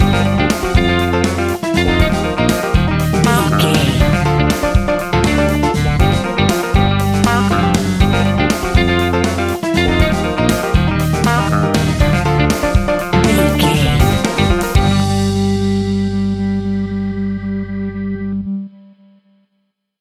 Aeolian/Minor
uptempo
brass
saxophone
trumpet